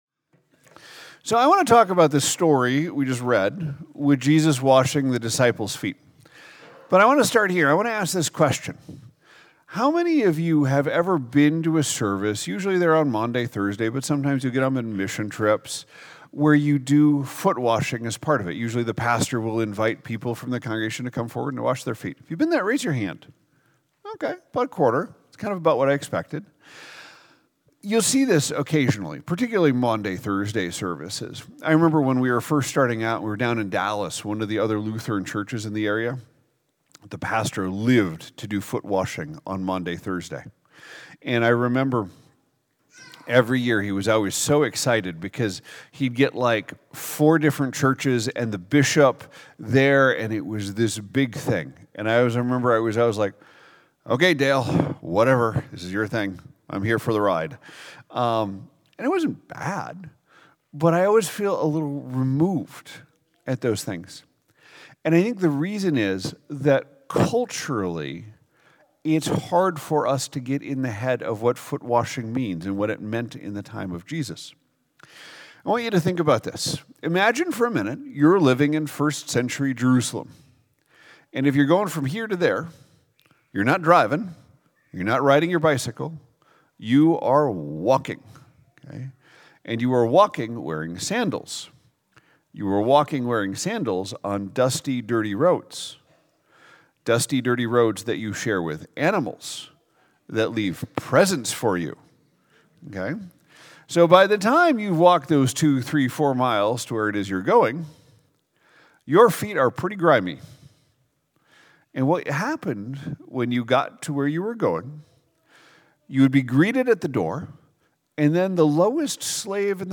2026 Gospel of John foot washing Jesus loves us serving sin Sunday Morning Jesus Washes Disciples Feet Scripture: John 13:1-17 Download